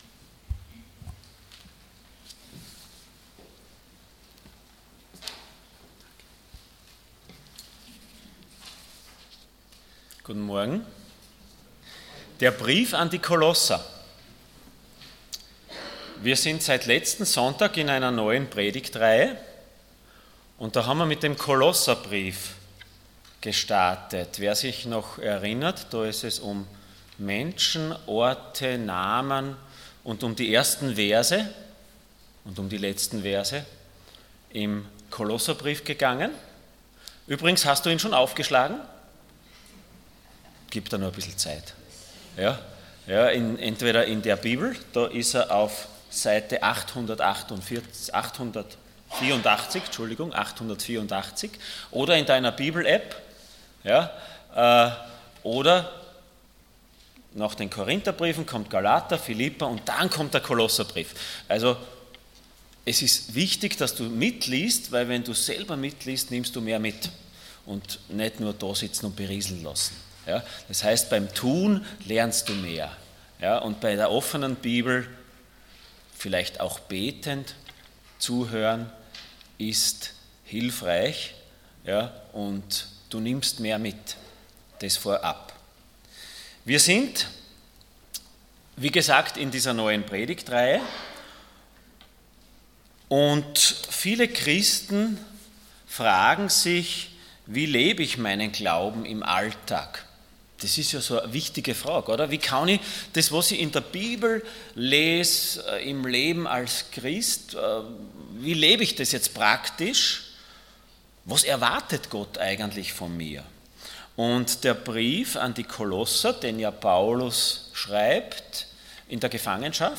Passage: Kolosser 1,9-14 Dienstart: Sonntag Morgen